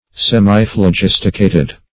Search Result for " semiphlogisticated" : The Collaborative International Dictionary of English v.0.48: Semiphlogisticated \Sem`i*phlo*gis"ti*ca`ted\ (s[e^]m`[i^]*fl[-o]*j[i^]s"t[i^]*k[=a]`t[e^]d), a. (Old Chem.) Partially impregnated with phlogiston.